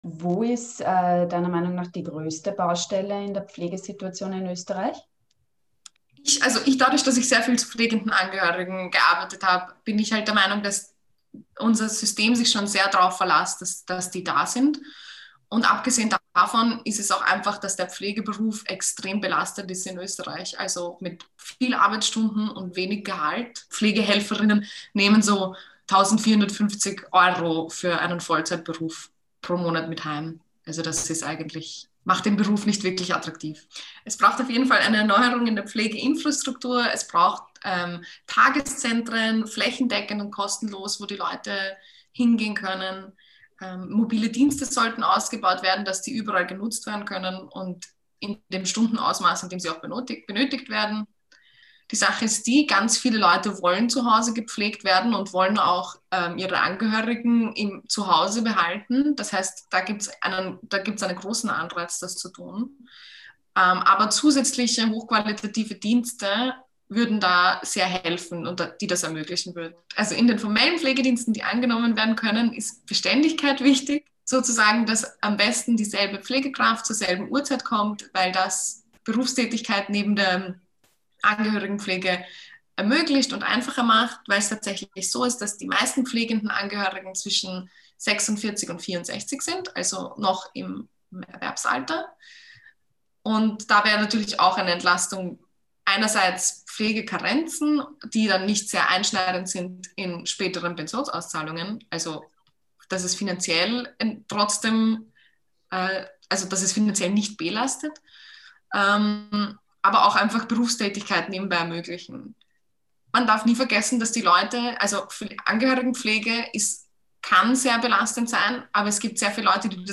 Zu Gast im Interview